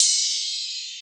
archived music/fl studio/drumkits/bvker drumkit/Cymbals/Rides